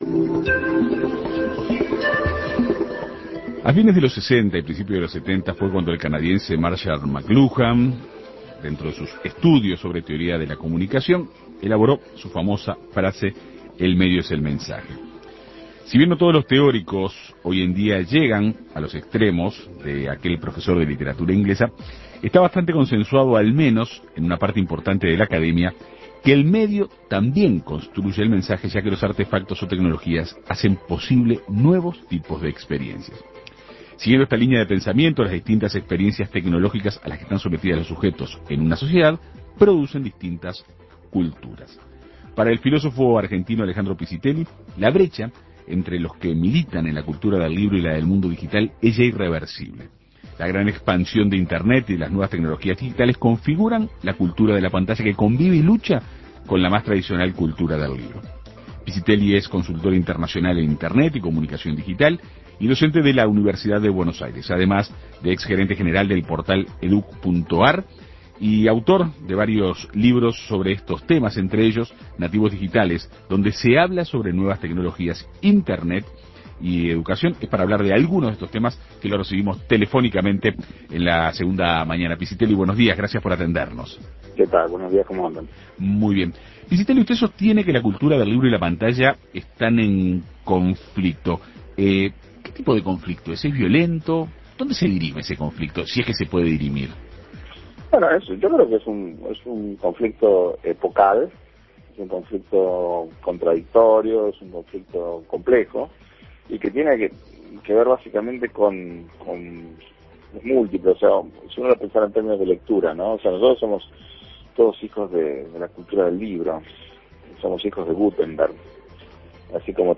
En Perspectiva Segunda Mañana dialogó con él para incursionar en el mundo de la comunicación digital y sus desafíos.